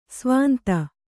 ♪ svānta